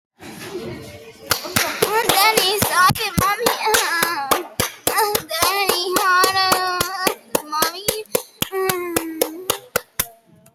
Fnaf Jumpscare Plz Play Sound Effect Download: Instant Soundboard Button